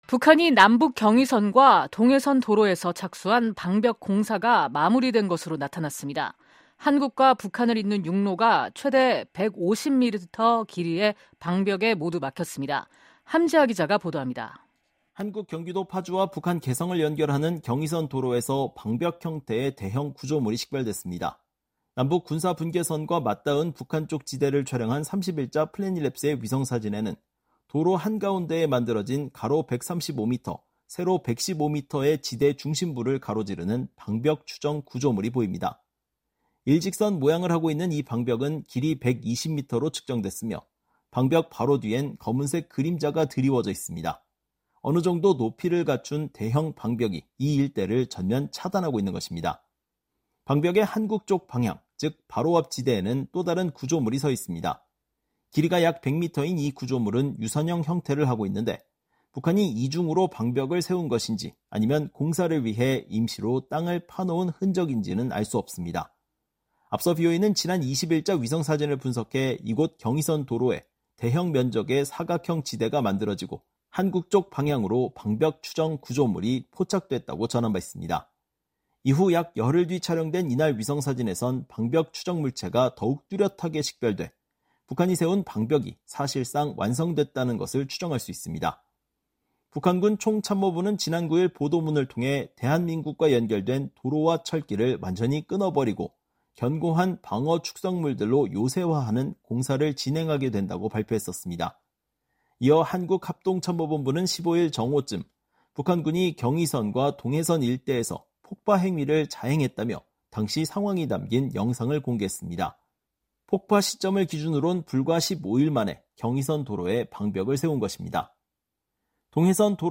기자가 보도합니다.